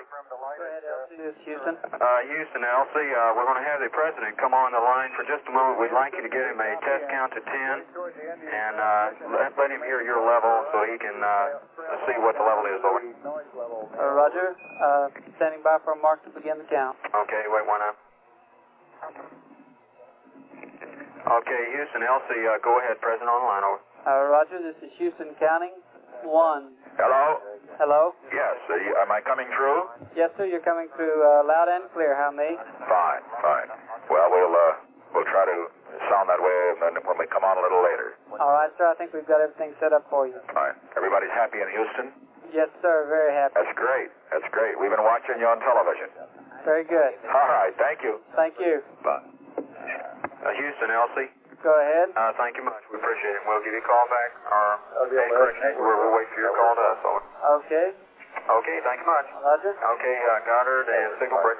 Network Controller
spoken with the President to check the circuit